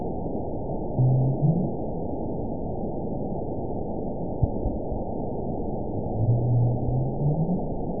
event 916294 date 12/29/22 time 09:18:04 GMT (1 year, 3 months ago) score 8.82 location INACTIVE detected by nrw target species NRW annotations +NRW Spectrogram: Frequency (kHz) vs. Time (s) audio not available .wav